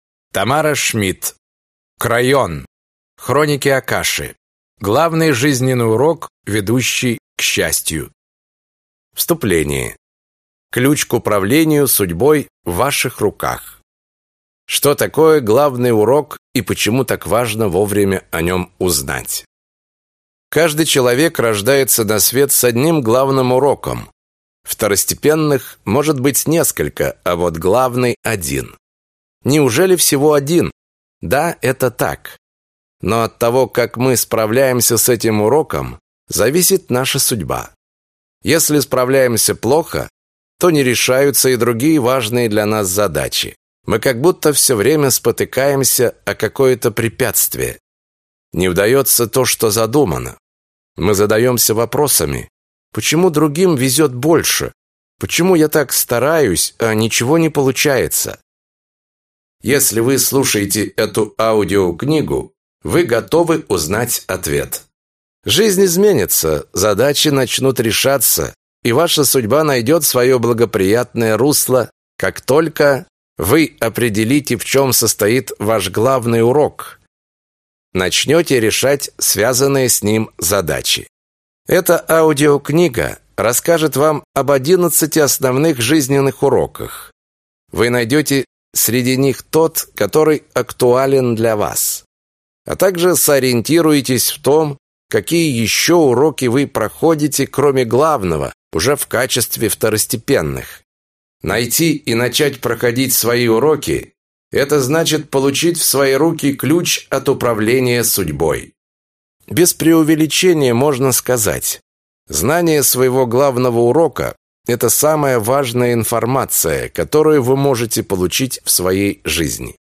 Аудиокнига Крайон. Хроники Акаши. Главный жизненный Урок, ведущий к счастью | Библиотека аудиокниг